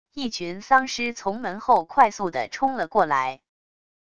一群丧尸从门后快速的冲了过來wav音频